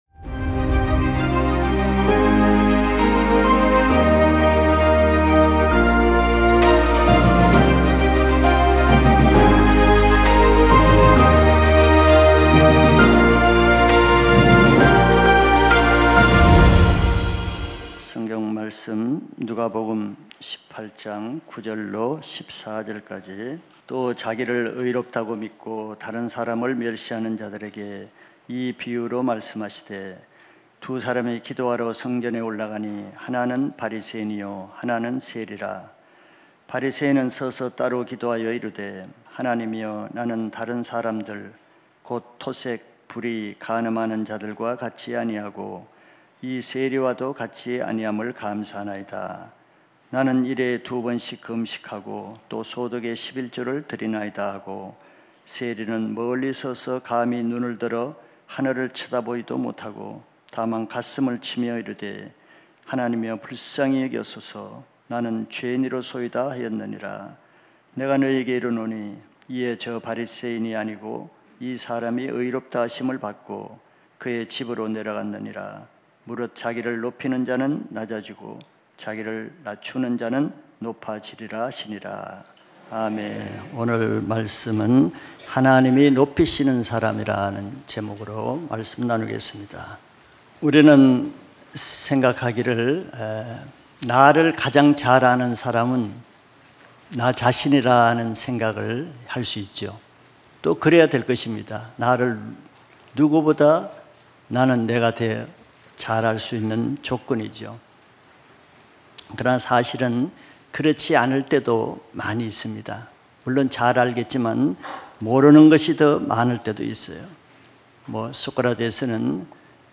2025년11월2일 주일예배말씀